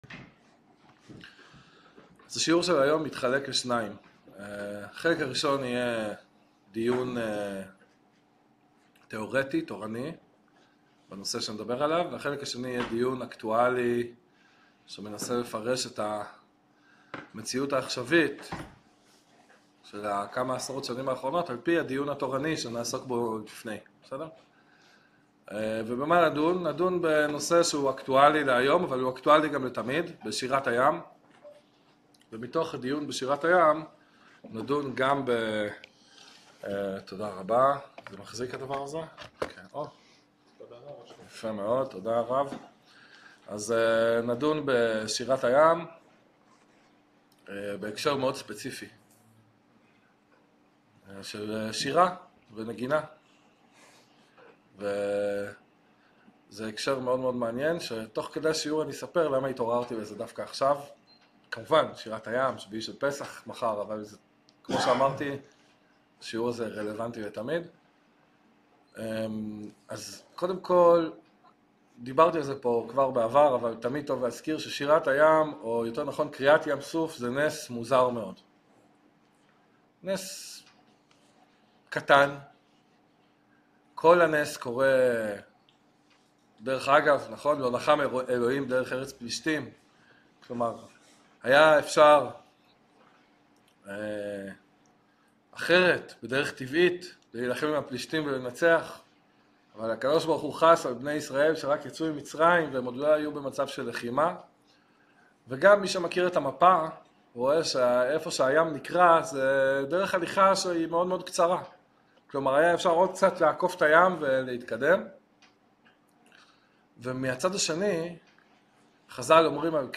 שיעור מרתק המבאר לעומק את הקשר בין מוזיקה לנבואה, מנתח את המוזיקה הישראלית, ומסביר למה היא ההוכחה הכי טובה שאנחנו בדרך להתחדשות הנבואה.